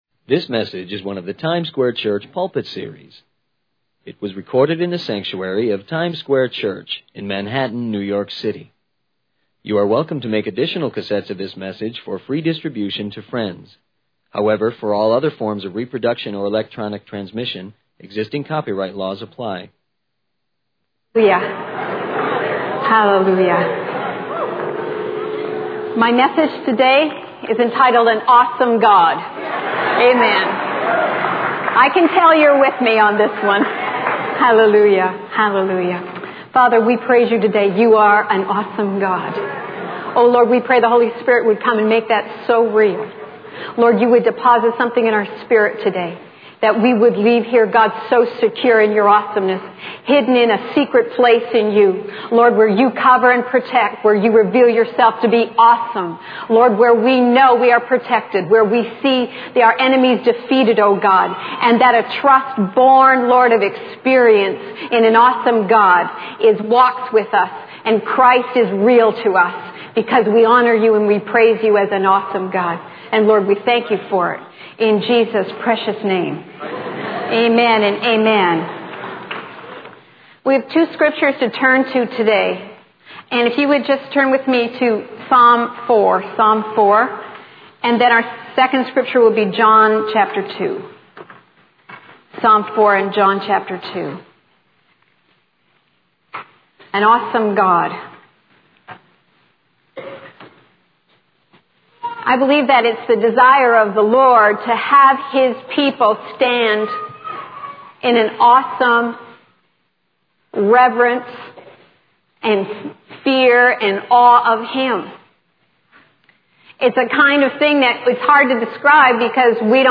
In this sermon, the speaker emphasizes the faithfulness and provision of God.